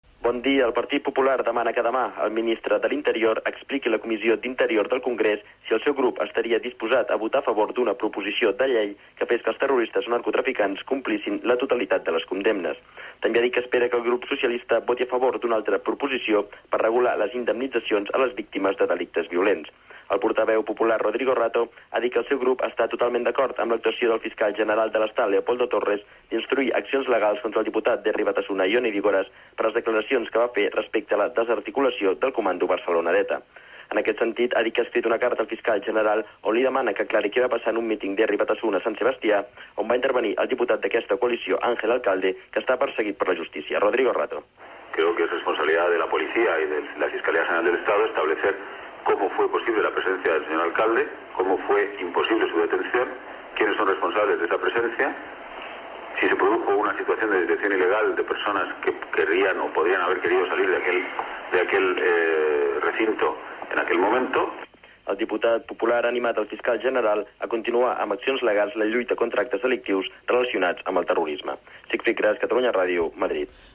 Crònica, des de Madrid, sobre la comissió d'Interior del Congrés i les declaracions de Jon Idigoras sobre la desarticulació del comando Barcelona d'ETA
Informatiu